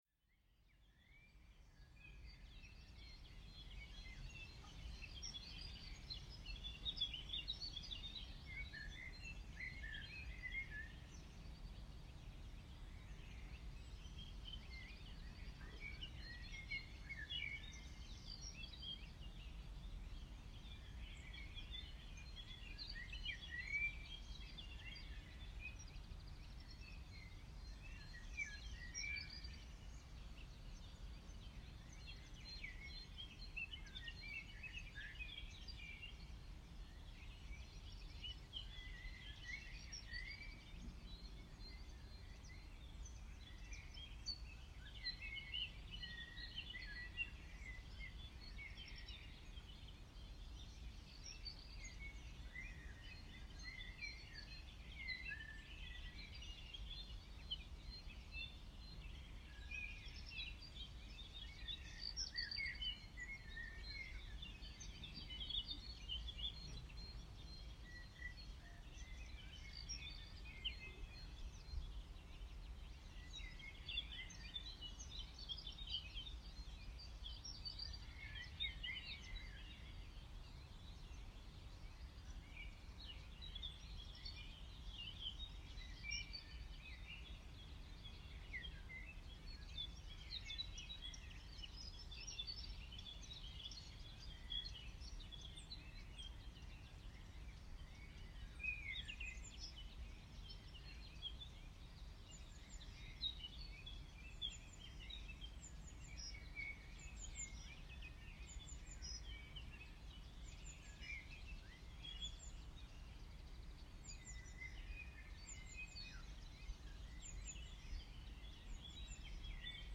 Gartenvögel
Gartenvögel.mp3